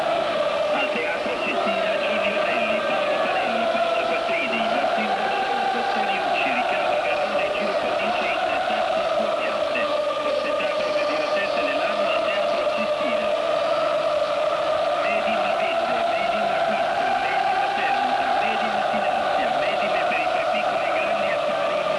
Semifinale Coppa dei Campioni